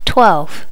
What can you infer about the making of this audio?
Update all number sounds so they are more natural and remove all clicks.